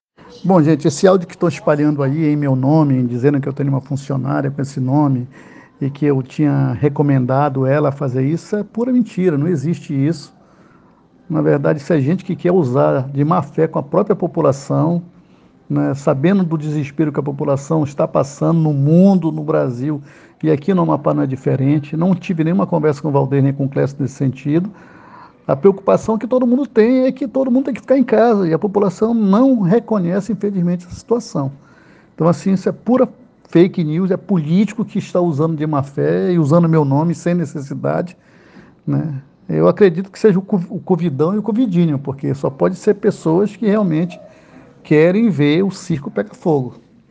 Ouça o áudio com a manifestação de Roberto Góes